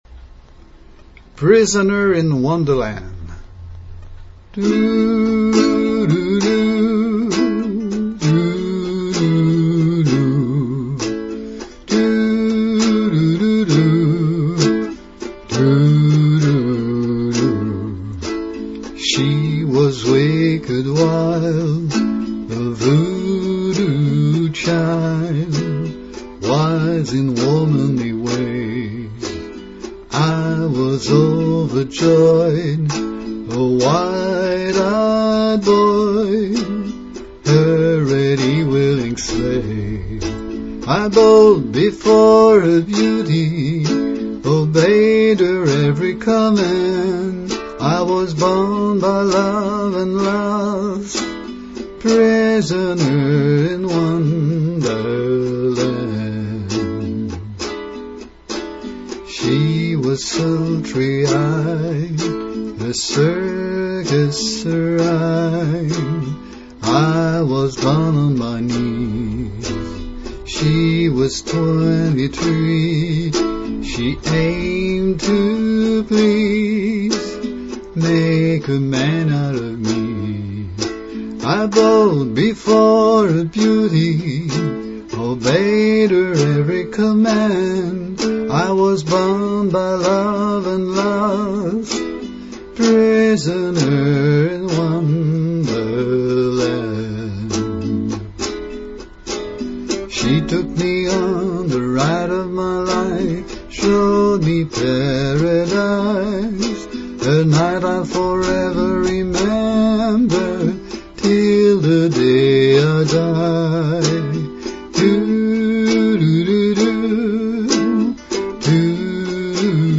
key of A, 6/8